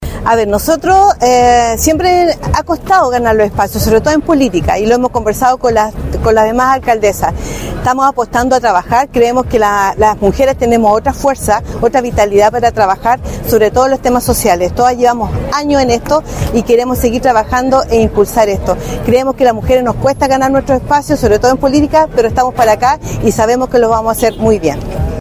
Ante más de 200 personas, entre ellas dirigentas, vecinas, artistas, académicas y autoridades, se conmemoró en el Faro Monumental de La Serena el acto oficial por el Día Internacional de la Mujer.
ACTO-8M-Uberlinda-Aquea-Alcaldesa-de-La-Higuera.mp3